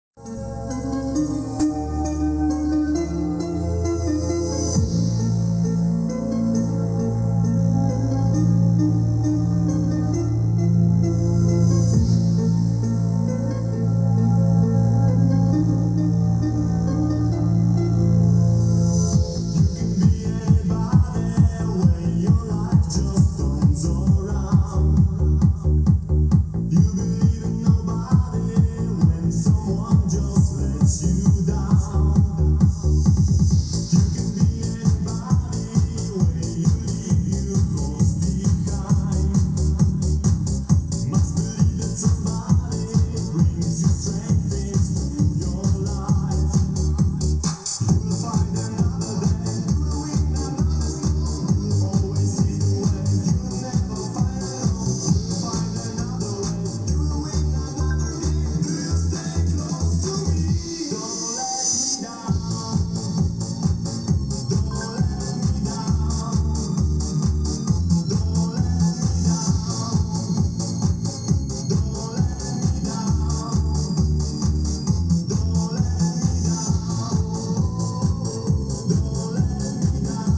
Piosenka z kasety